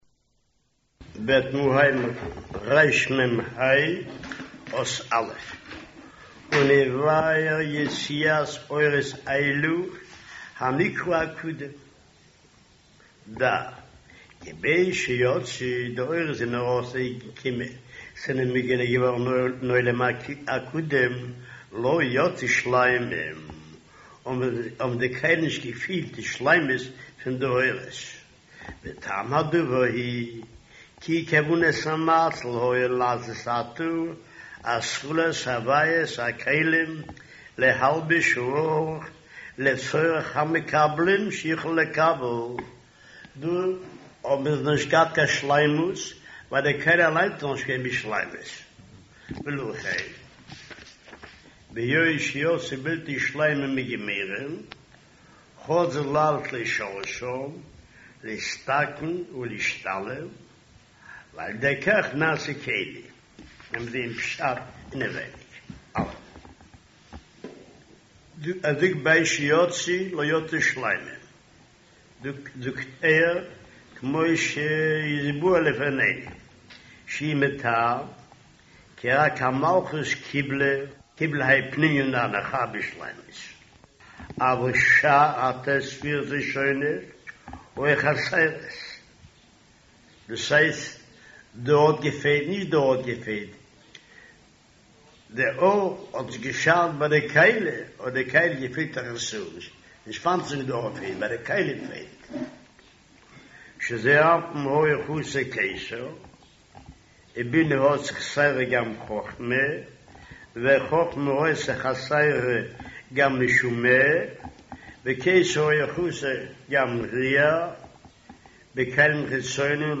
אודיו - שיעור מהרב"ש